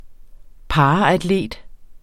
Udtale [ ˈpɑːɑadˌleˀd ]